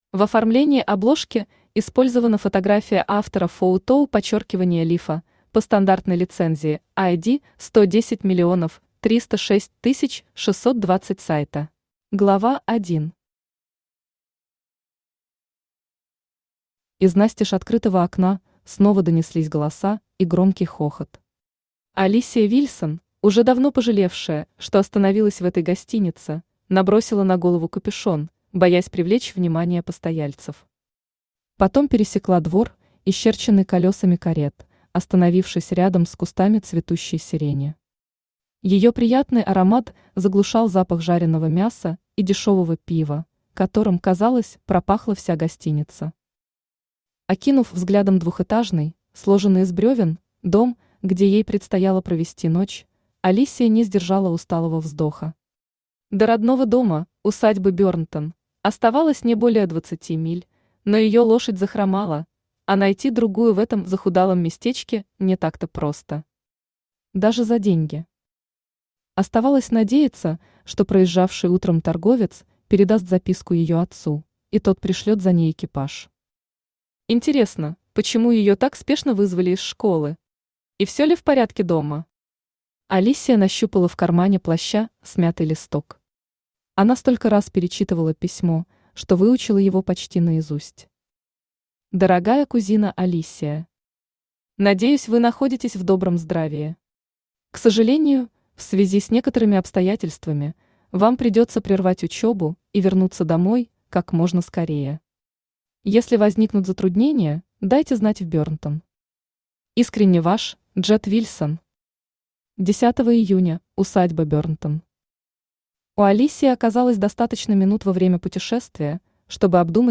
Аудиокнига Жемчужина страсти | Библиотека аудиокниг
Aудиокнига Жемчужина страсти Автор Татьяна Абиссин Читает аудиокнигу Авточтец ЛитРес.